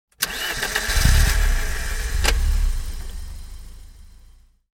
جلوه های صوتی
دانلود صدای ماشین 19 از ساعد نیوز با لینک مستقیم و کیفیت بالا
برچسب: دانلود آهنگ های افکت صوتی حمل و نقل دانلود آلبوم صدای انواع ماشین از افکت صوتی حمل و نقل